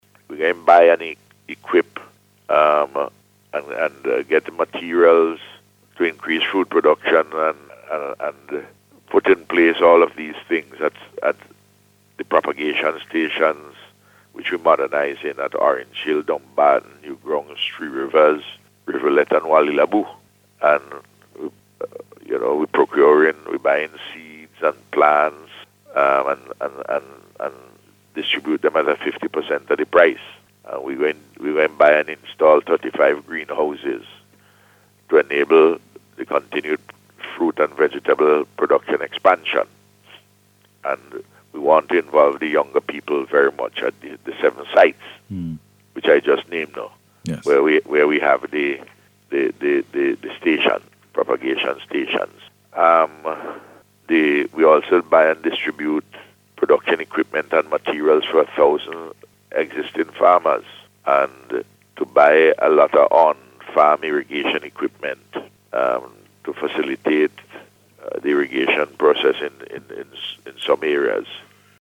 This assurance came from Prime Minister Dr. Ralph Gonsalves, as he discussed the Government’s upcoming plans and programmes, during NBC’s Face to Face Programme yesterday.